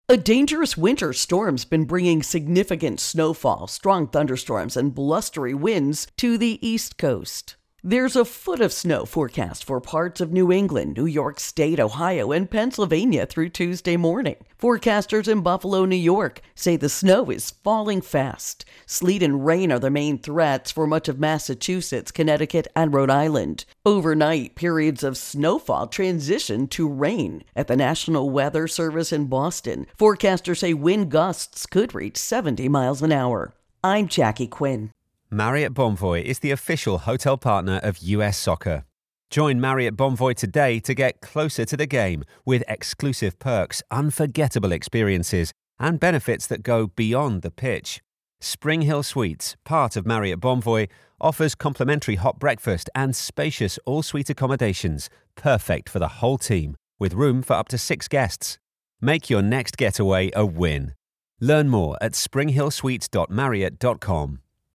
Winter Weather Northeast Intro and Voicer